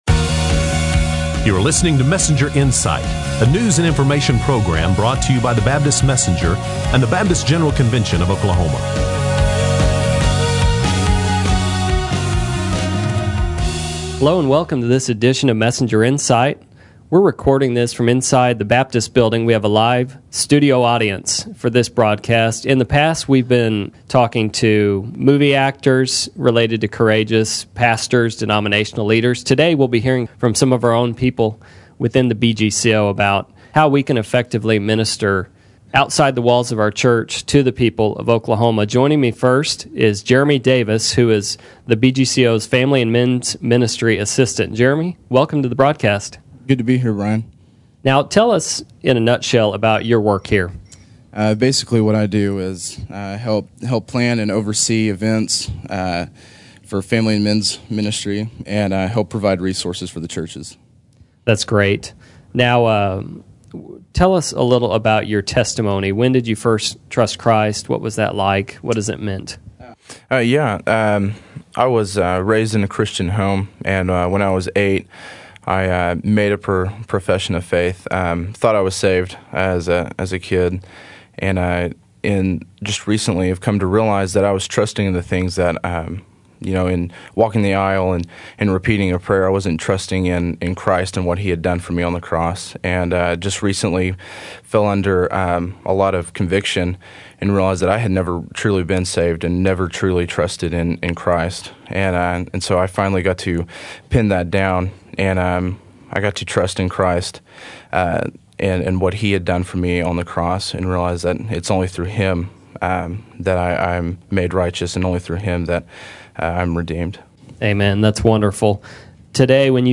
In this new podcast, BGCO staff discuss ways to make a ministry impact outside the church’s walls.